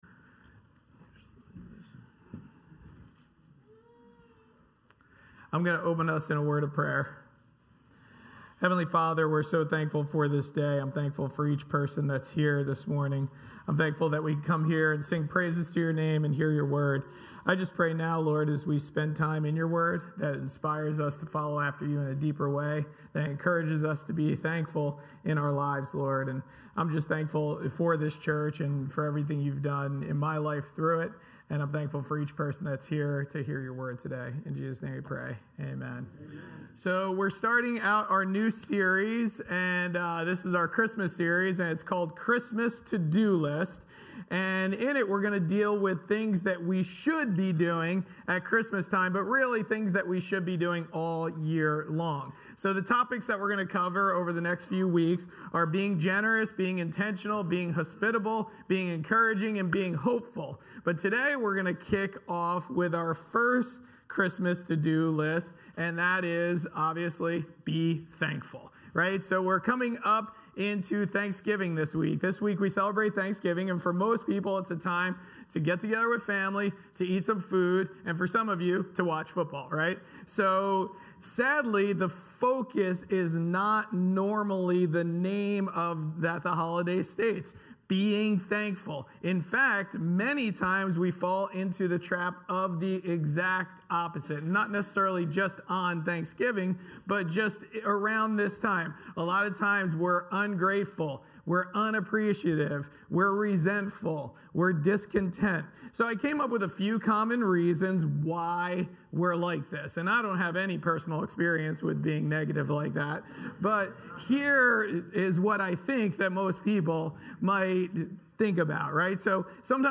Sermons | Forked River Baptist Church